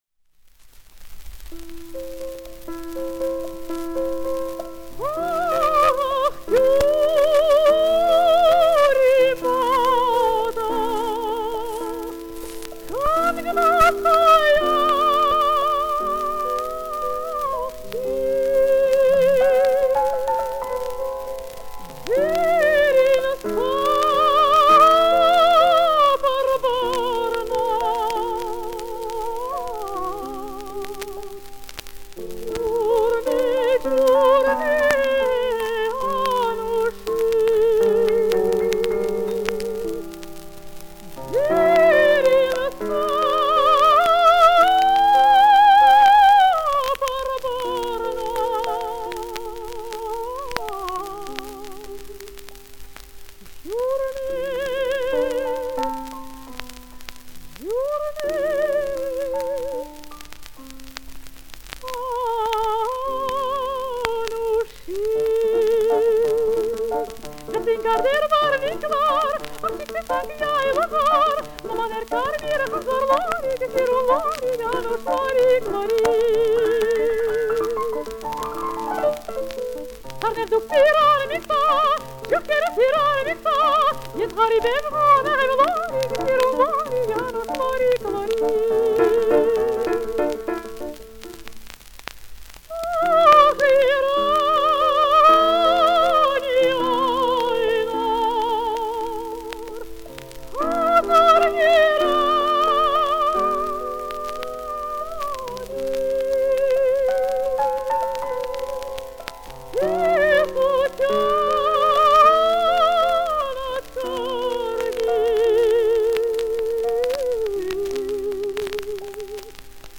ф-но
Источник грампластинка